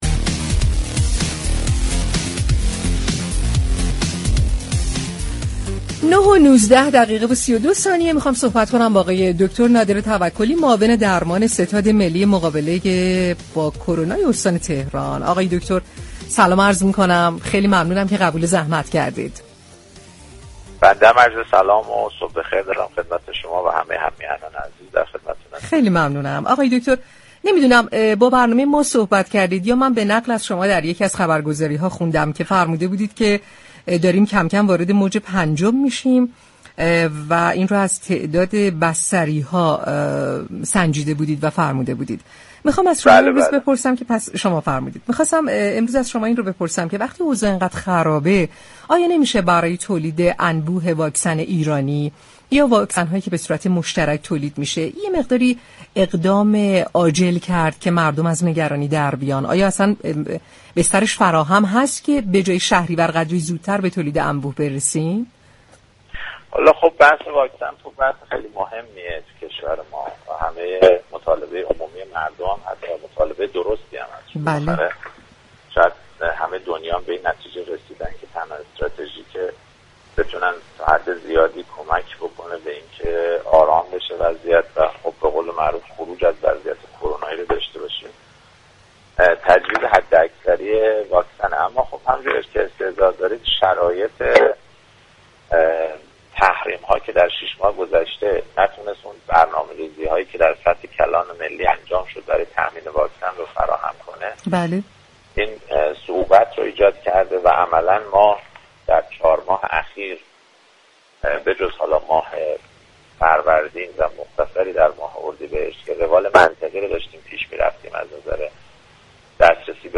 به گزارش پایگاه اطلاع رسانی رادیو تهران، نادر توكلی معاون ستاد مقابله با كرونای تهران در گفتگو با برنامه تهران ما سلامت با اشاره به افزایش تعدا بستری‌ها و شروع موج پنجم اقدام نهادهای مربوطه برای تولید سریعتر واكسن ایرانی در حجم انبوه گفت: بحث واكسن بسیار مهم است و همه دنیا به به این نتیجه رسیده اند كه تنها استراتژی كه بتواند به ما برای خروج از وضعیت كرونایی كمك كند تجویز حداكثری واكسن است اما بواسطه تحریم‌های موجود امكان رسیدن به برنامه ریزی‌های كلان ملی برای تامین واكسن در شش ماه گذشته تحت الشعاع قرار گرفته است.